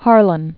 (härlən), John Marshall 1833-1911.